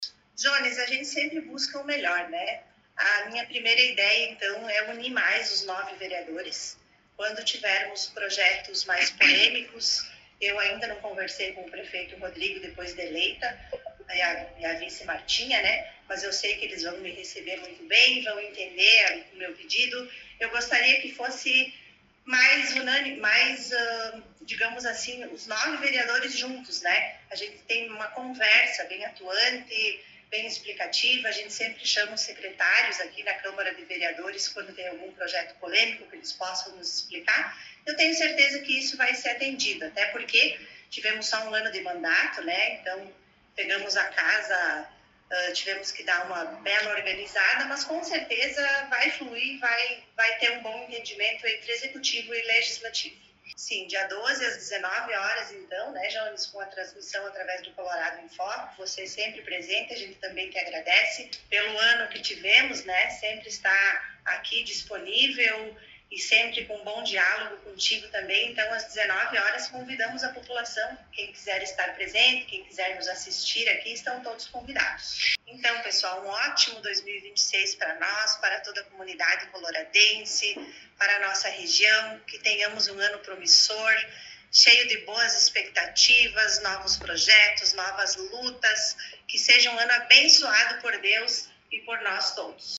A nova Presidente do Poder Legislativo concedeu entrevista